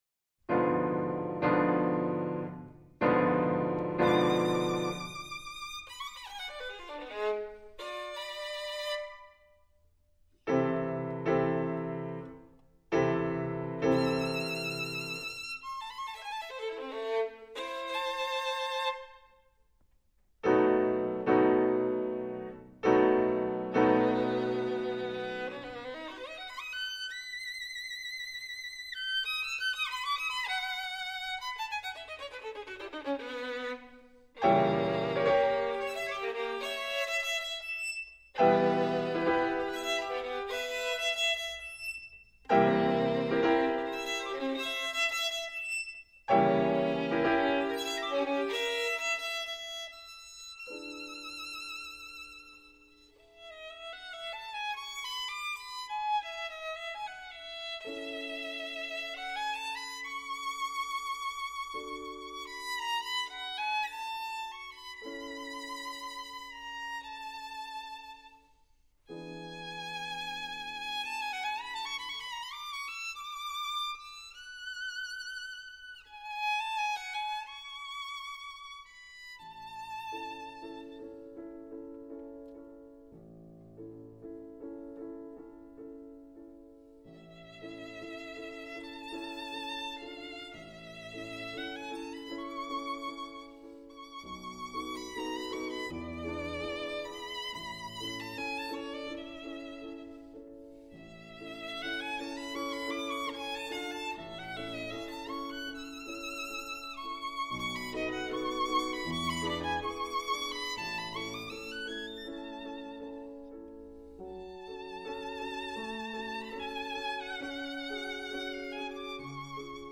violino
pianoforte